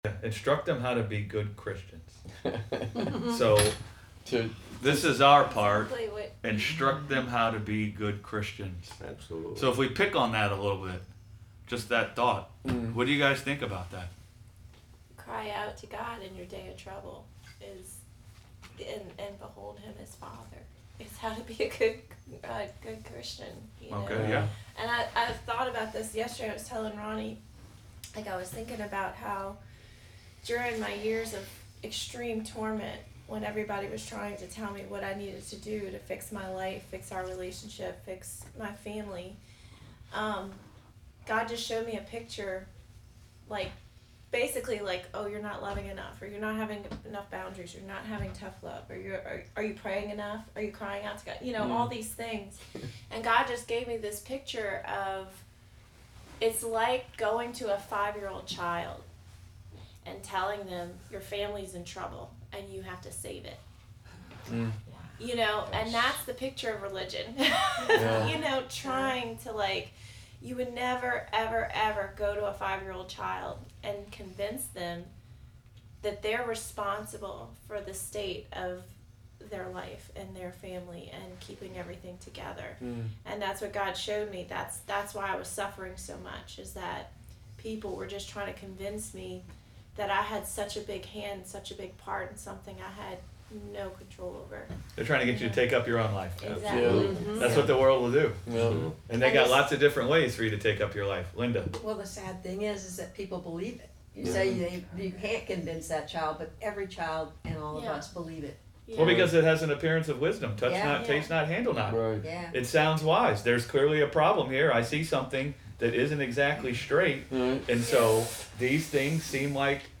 Sunday Bible Study: What is a Good Christian?